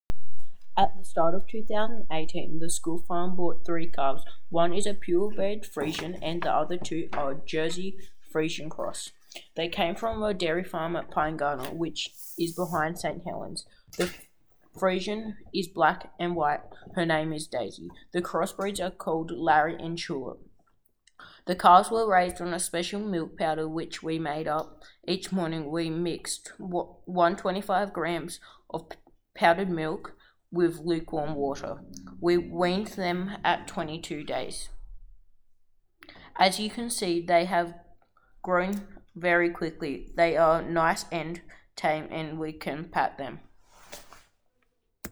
Cows at St Marys District School Farm